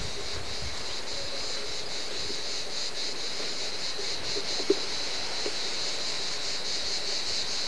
a.) mehrere "Cigale commune"
cigale.aif